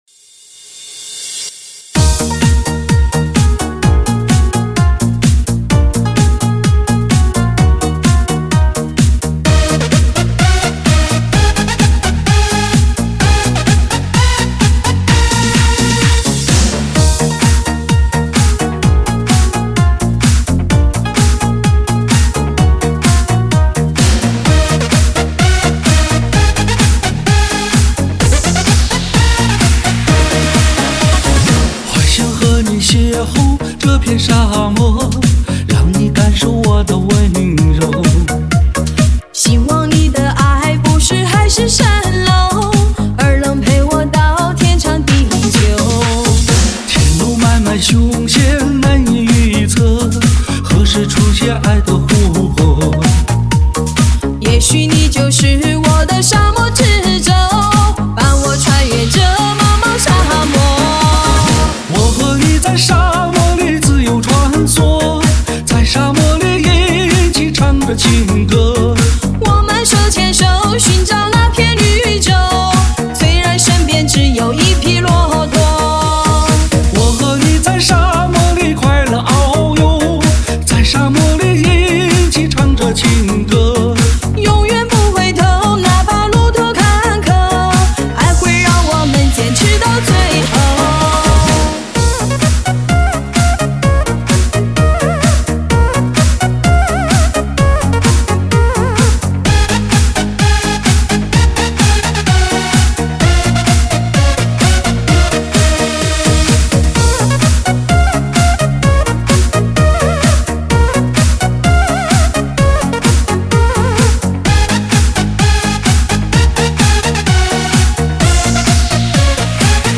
强势推出迪吧新舞曲